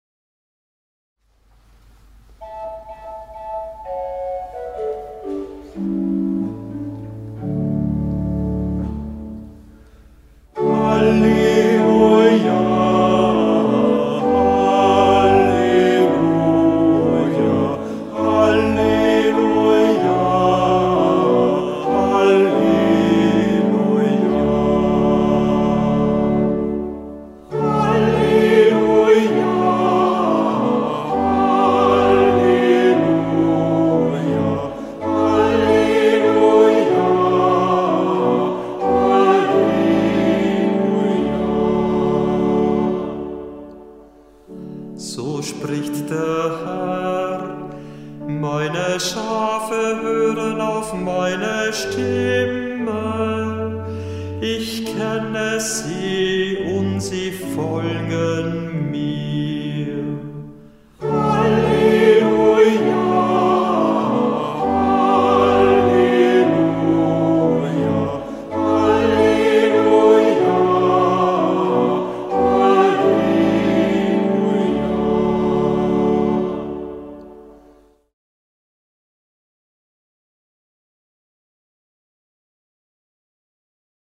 Kantor der Verse